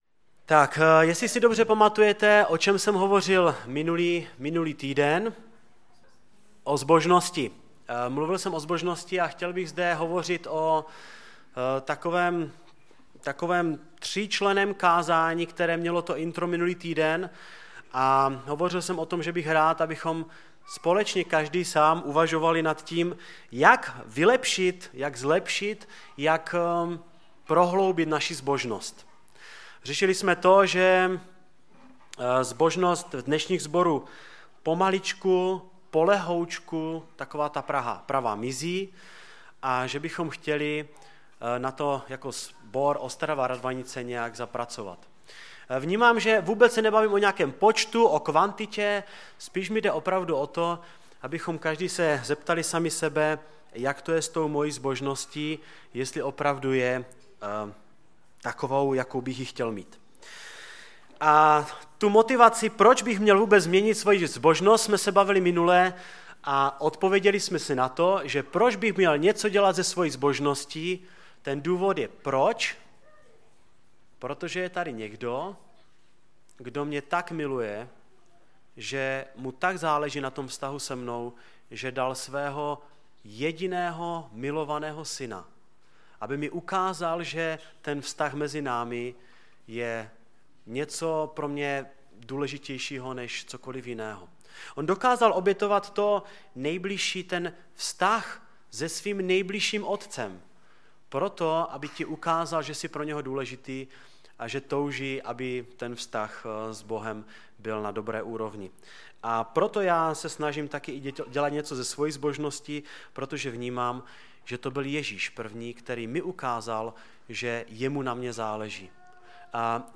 Kázání
ve sboře Ostrava-Radvanice.